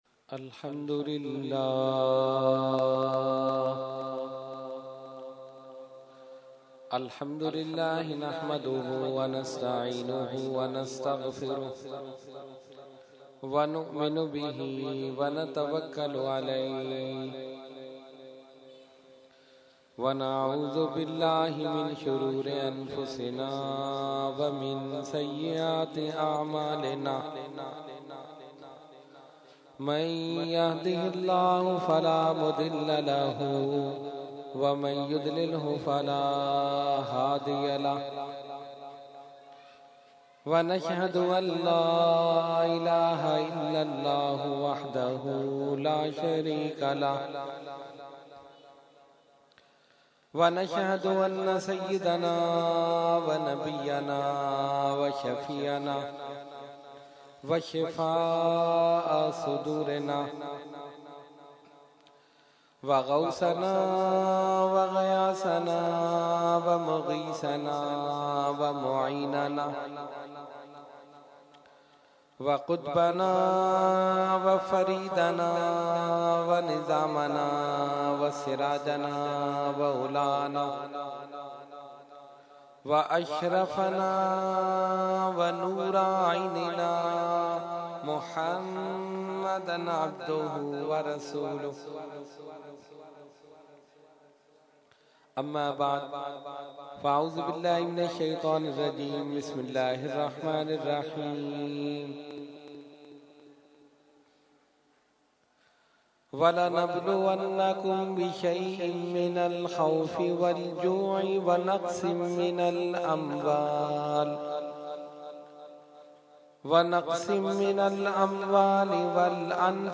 Category : Speech | Language : UrduEvent : Muharram ul Haram 2014